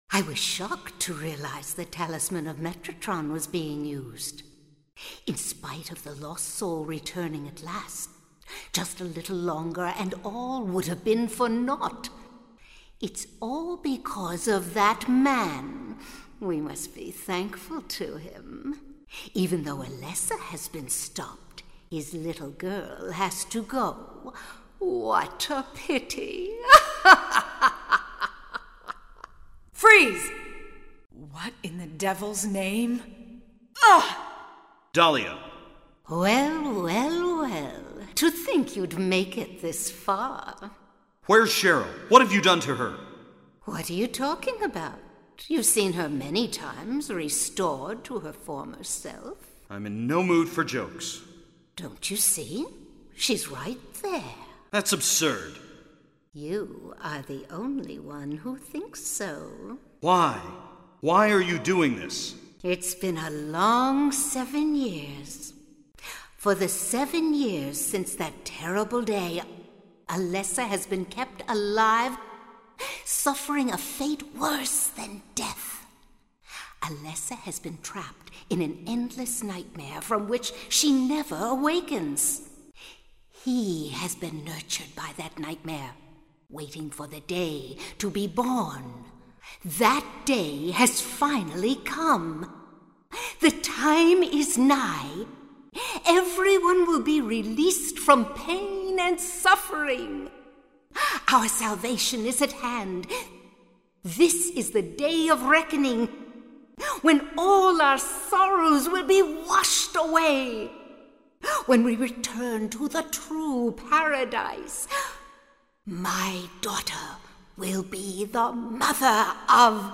VOICE AND CUTSCENES